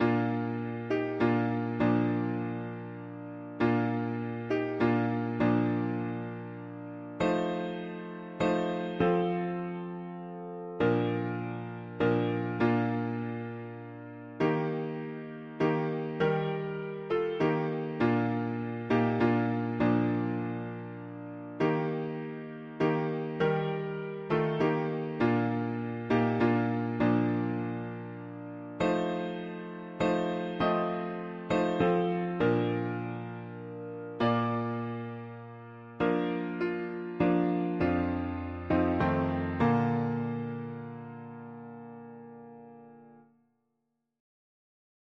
All is calm, all is bright, round yon virgin mother and child, holy Infant, so tender and mild, sleep in heavenly peace, sle… english christian christmas 4part chords